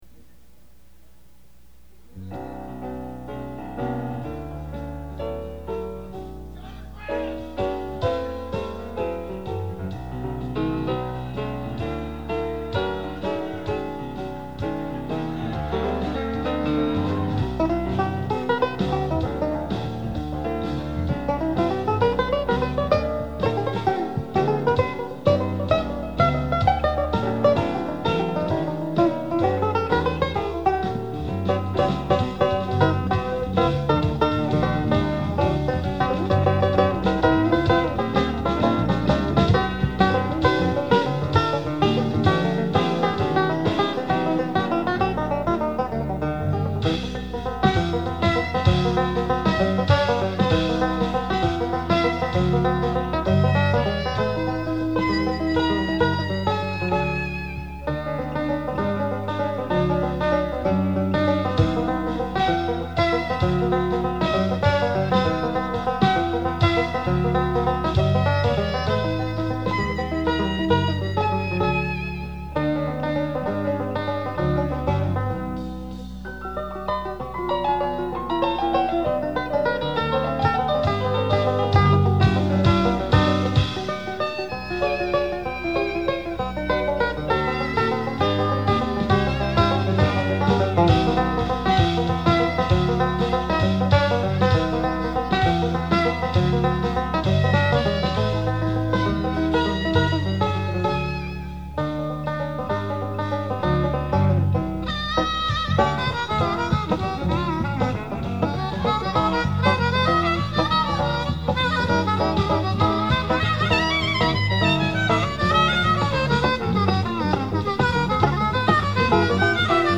May 19, 1990 – Green Acres, Bostic, NC